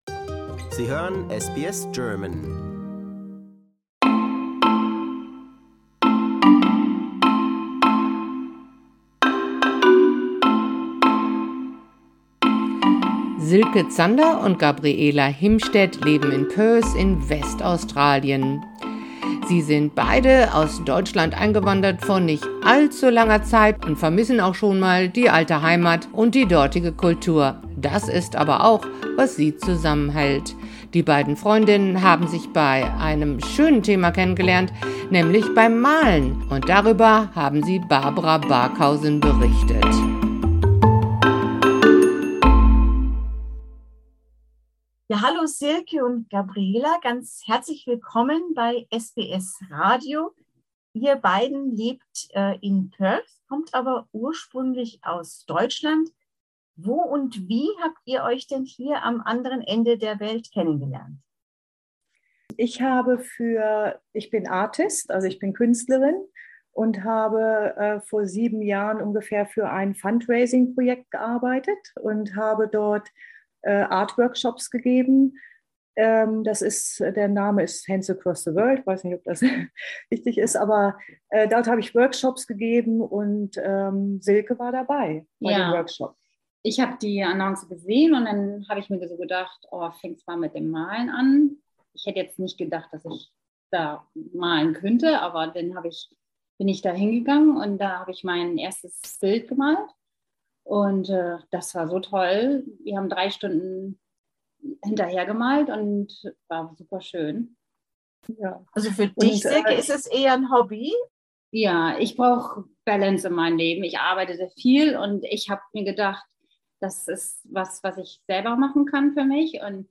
Die beiden haben sich bei einem besonders schönen Thema kennengelernt, nämlich dem Malen. Und davon berichten sie uns jetzt ein wenig mehr.